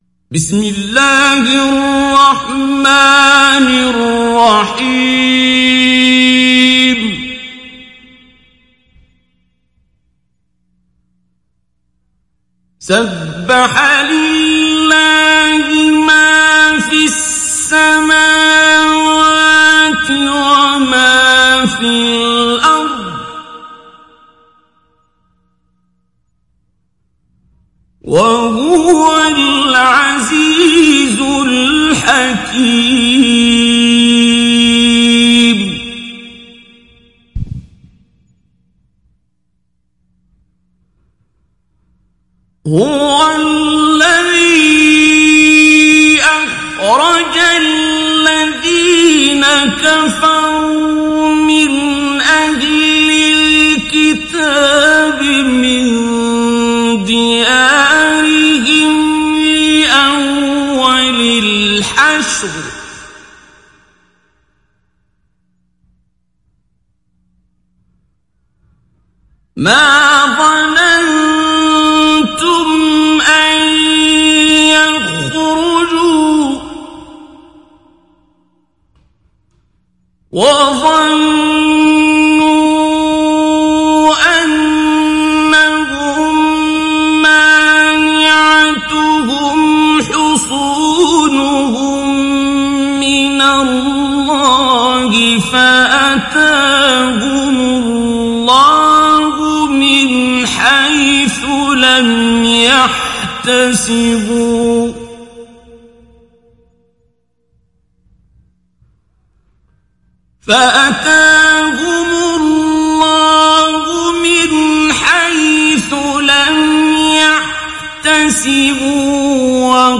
دانلود سوره الحشر mp3 عبد الباسط عبد الصمد مجود روایت حفص از عاصم, قرآن را دانلود کنید و گوش کن mp3 ، لینک مستقیم کامل
دانلود سوره الحشر عبد الباسط عبد الصمد مجود